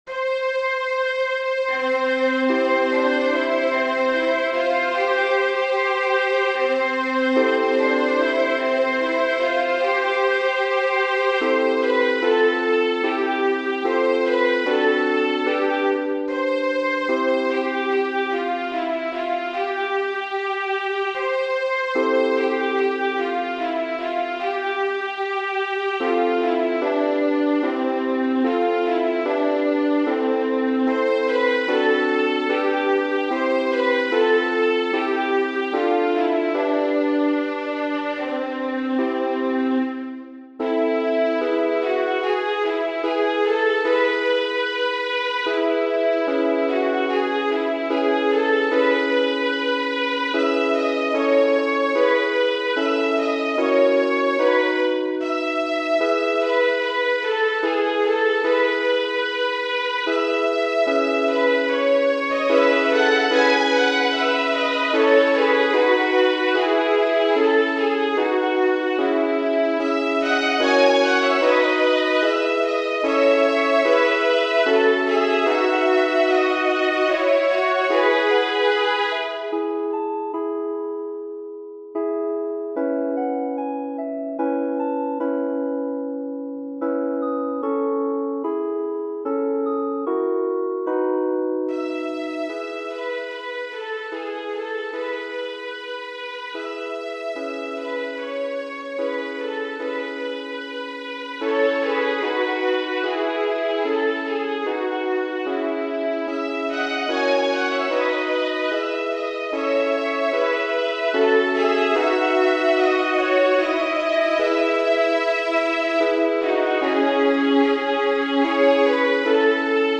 Holst, G. Genere: Religiose Testo di Wlliam Henry Draper (da S, Francesco d'Assisi) Armonizzazione di Gustav Holst della melodia tedesca "Lasst Uns Erfreuen" del XVII sec. 1.